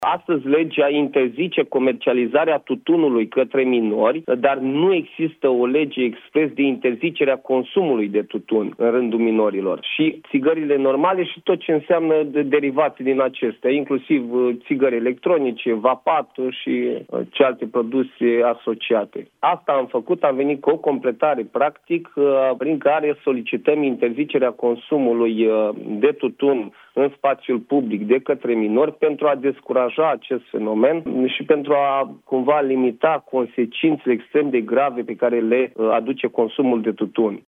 Ștefan Tănasă, deputat USR: „Astăzi legea interzice comercializarea tutunului către minori, dar nu există o lege expres de interzicere a consumului de tutun în rândul minorilor”